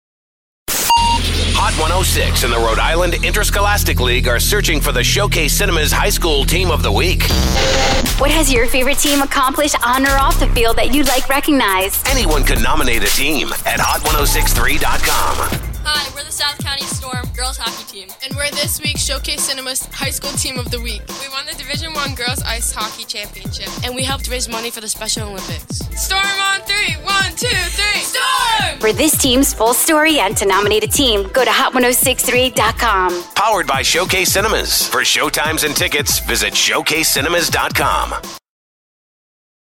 Hot 106 On-Air Spot